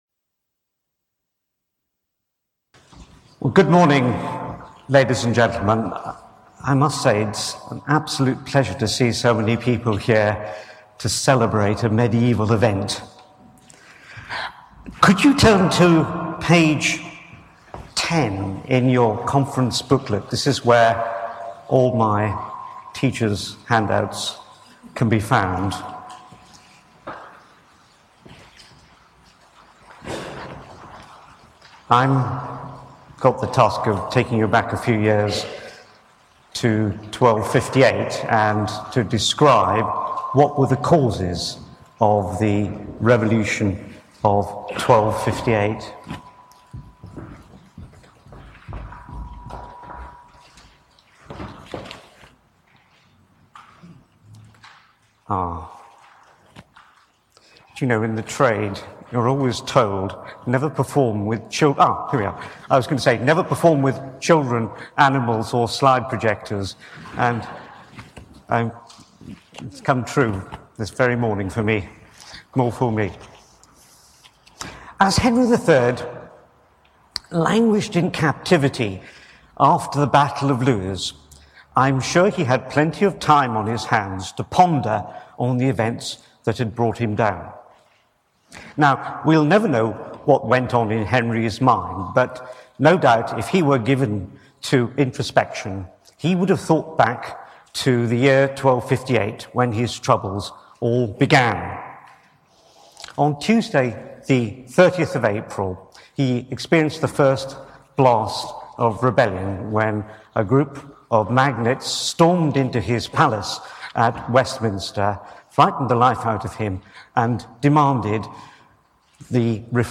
The first part of a 2012 talk